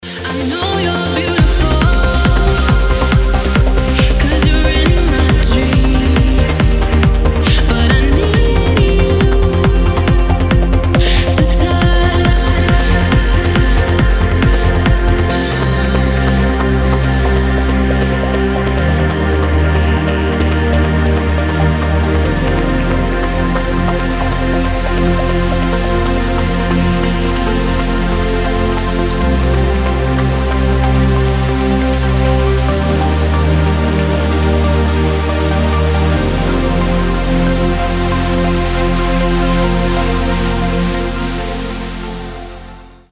• Качество: 320, Stereo
dance
Electronic
Trance
vocal